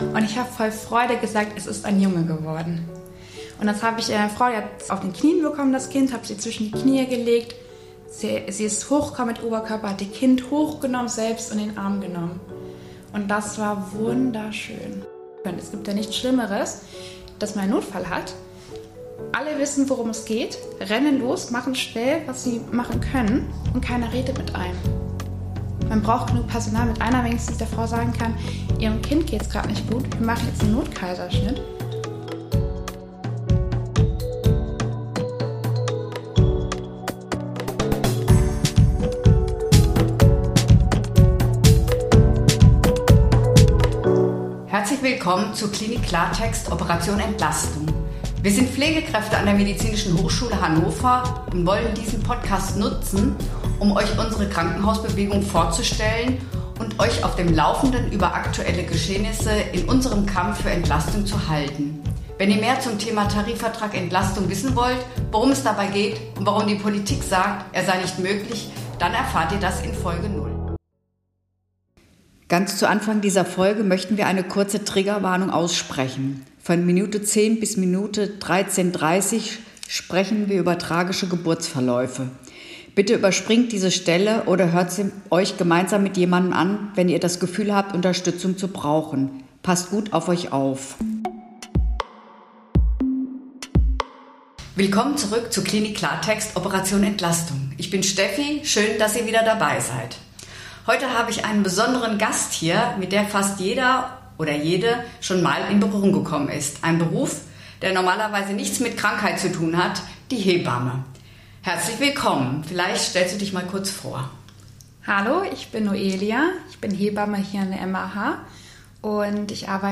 Hebamme – Ein Interview